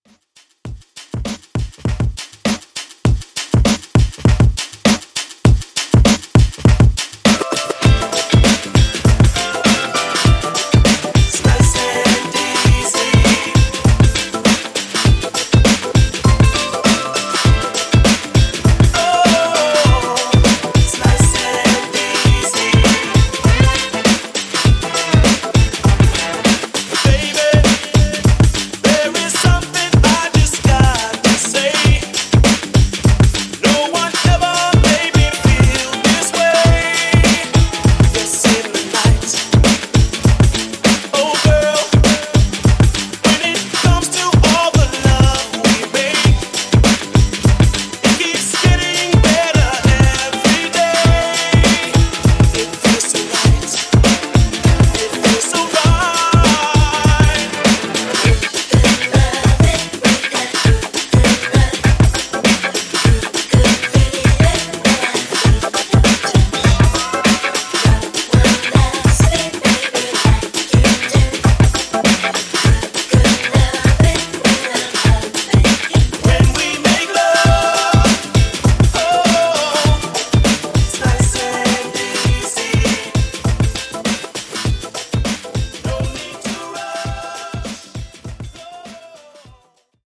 90s Redrum)Date Added